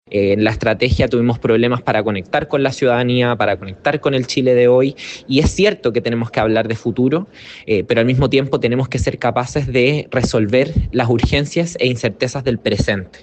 La diputada Emilia Schneider manifestó que en la campaña faltó destacar los logros que ha tenido el partido durante la administración del Presidente Gabriel Boric.